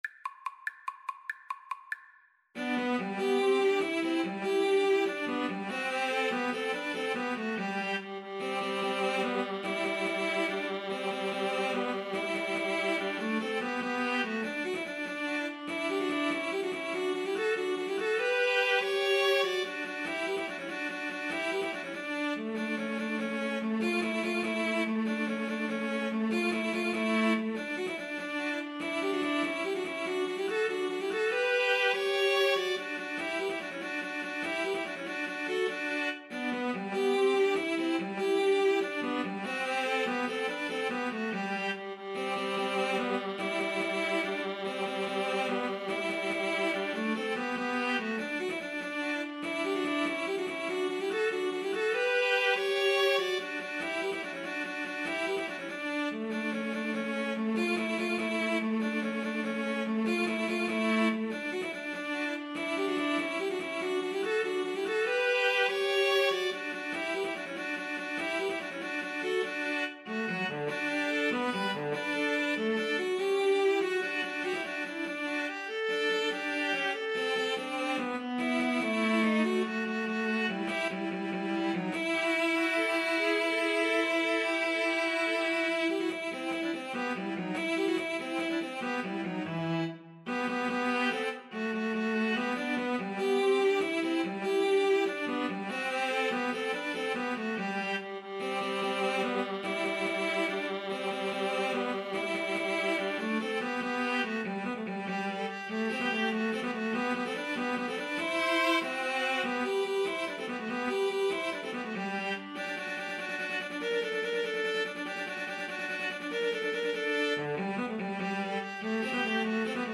String trio
Baryton Viola Cello
Tempo Marking: I: Presto Assai .=96
Time Signature: 3/8
Score Key: G major (Sounding Pitch)
Style: Classical